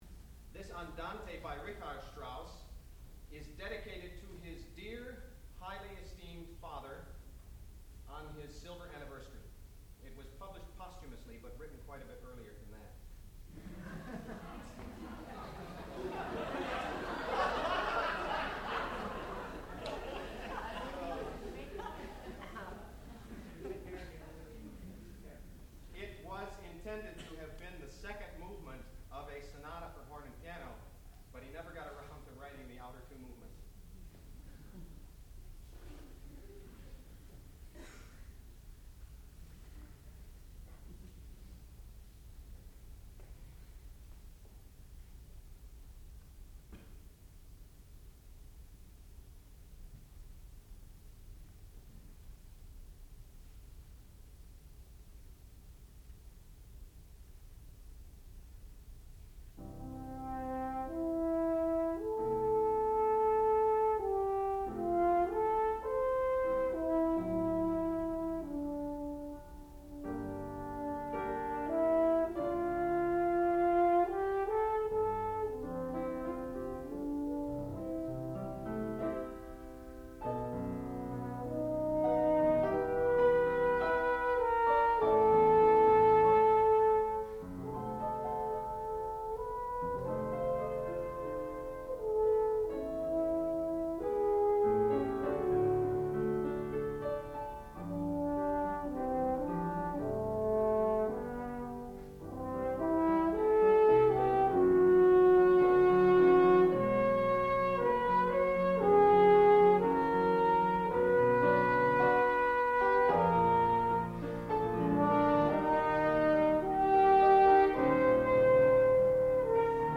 sound recording-musical
classical music
piano
horn